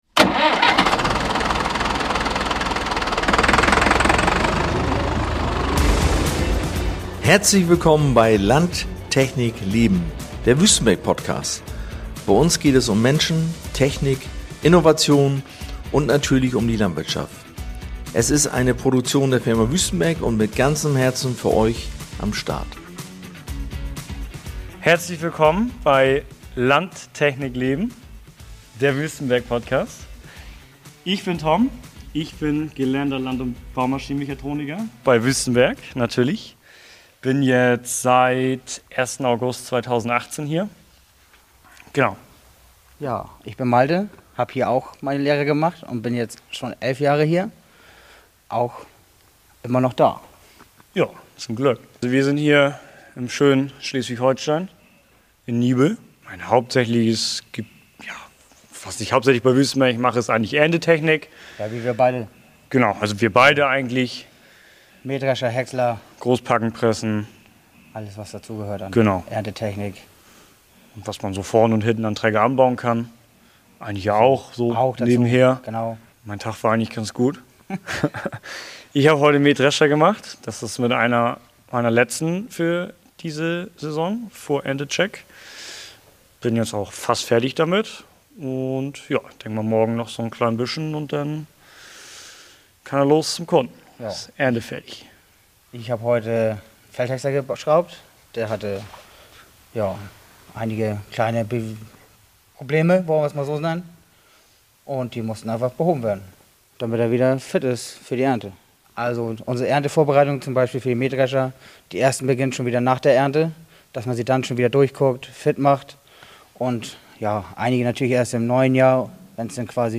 Live aus der Werkstatt in Niebüll